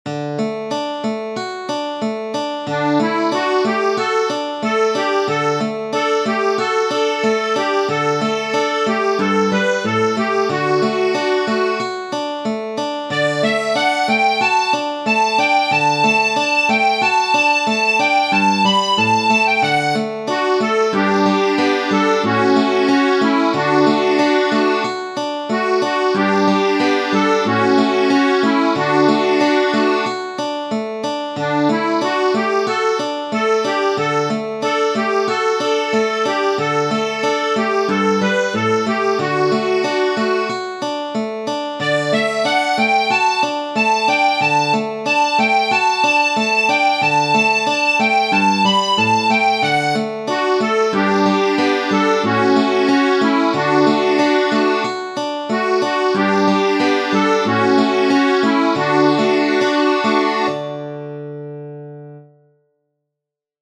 Tradizionale Genere: Folk Letra de anònimo ¿Dónde vas, campurrianuca?